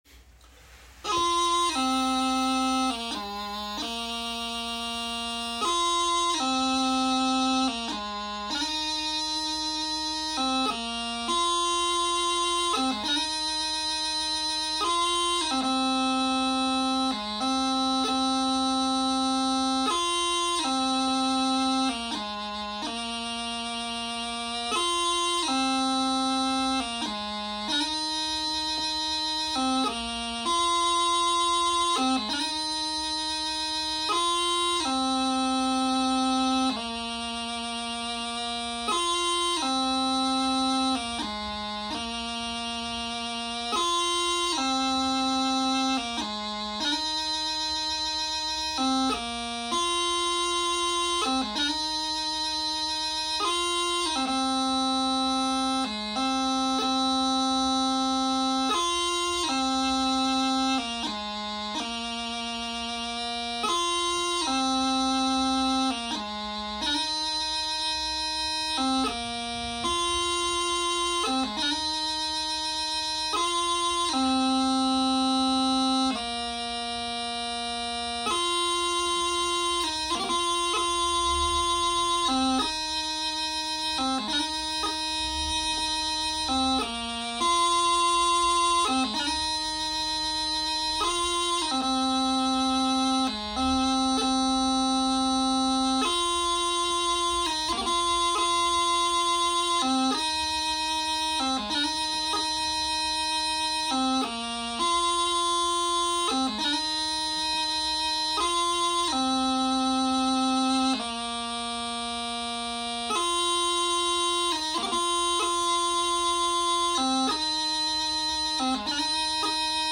Below the tune I play three excerpts on the practice chanter to let people hear how it goes.